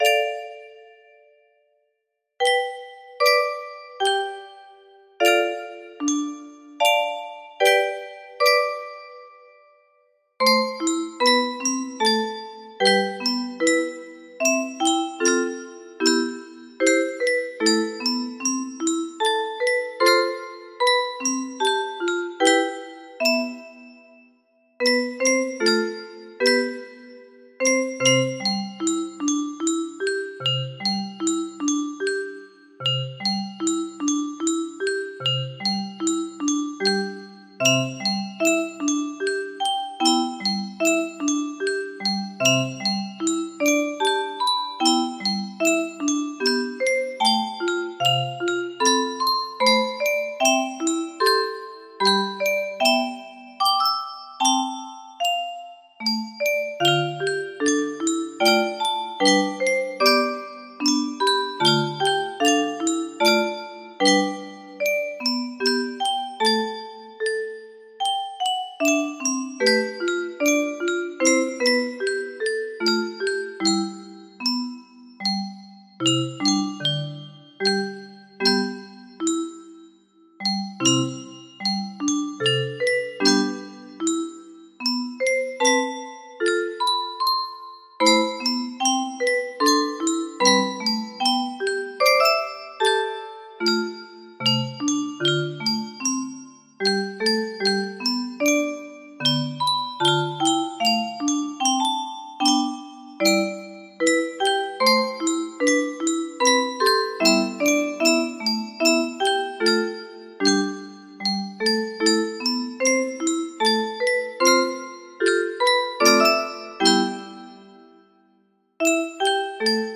Fully playable on the 30-note music box.